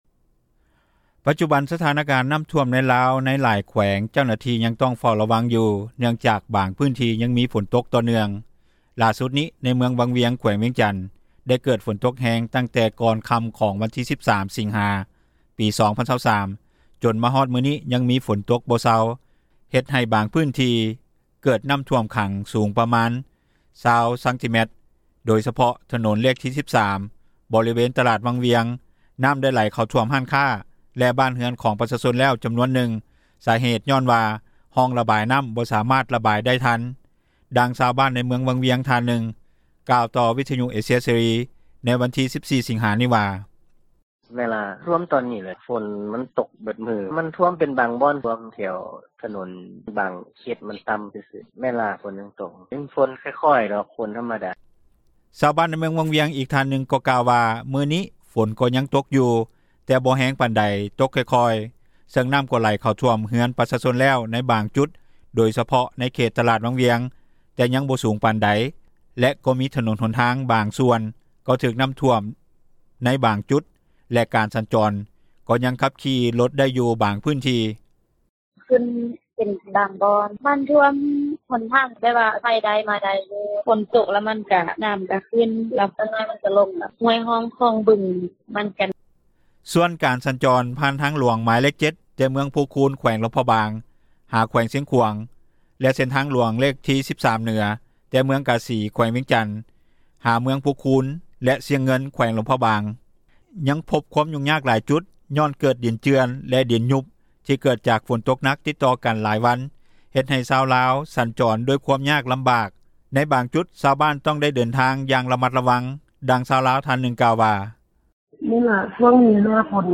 ດັ່ງຊາວບ້ານ ທ່ານນຶ່ງ ກ່າວວ່າ:
ດັ່ງເຈົ້າໜ້າທີ່ ທີ່ກ່ຽວຂ້ອງໃນແຂວງໄຊຍະບູຣີ ກ່າວວ່າ: